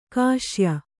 ♪ kāśya